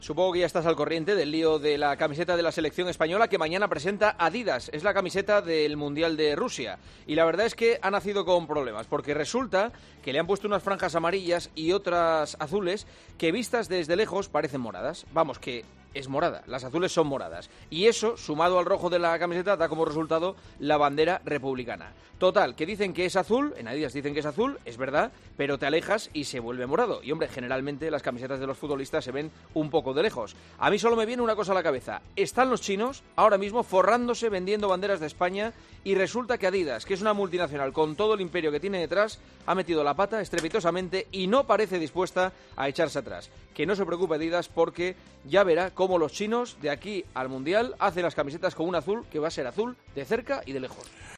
Polémica en redes por la camiseta "republicana" de España para el Mundial de Rusia, en el comentario de Juanma Castaño en 'Herrera en COPE'.